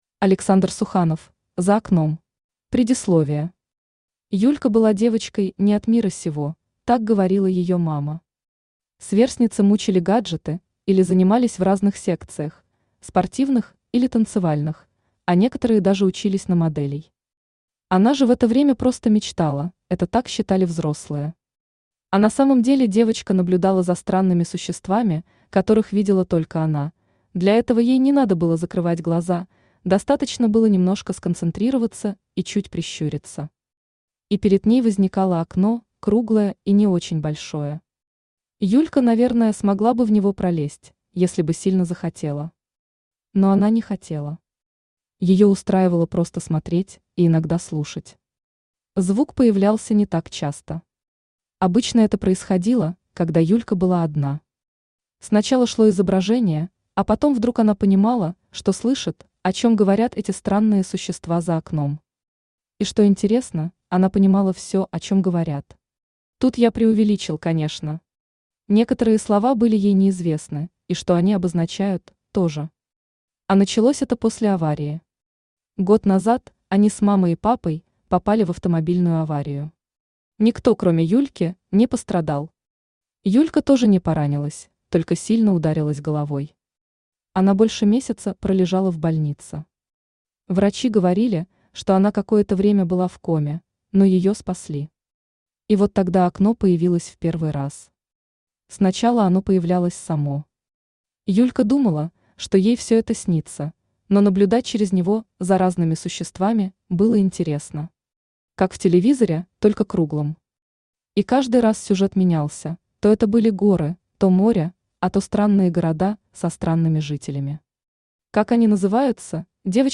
Aудиокнига За окном Автор Александр Суханов Читает аудиокнигу Авточтец ЛитРес.